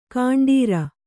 ♪ kaṇḍīra